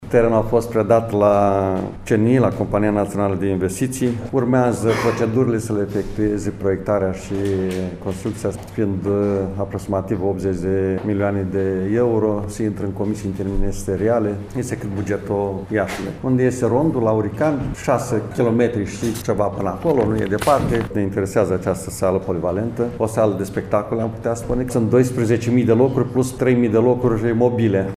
Suma necesară pentru realizarea investitiţiei se cifrează la 80 milioane de euro, a declarat preşedintele Consiliului Judeţean Iaşi, Maricel Popa: